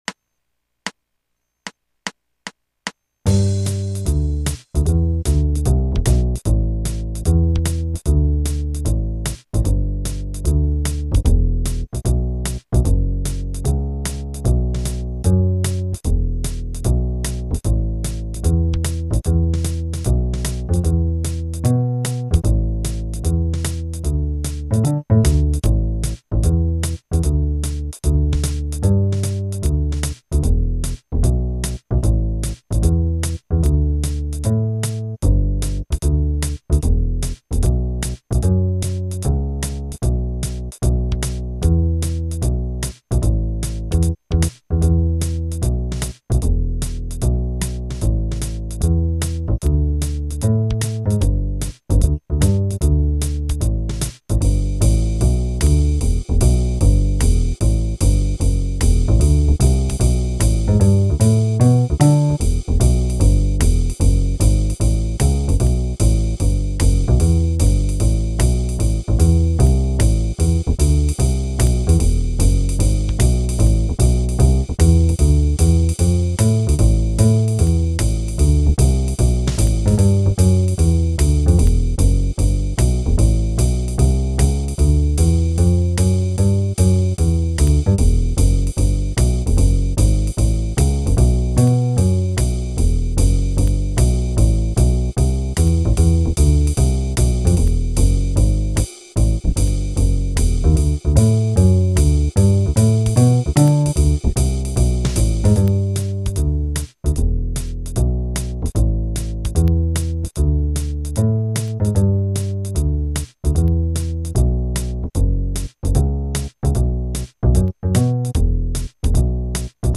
2. The second track is in swing style:
All rhythms in this exercise are to be swung.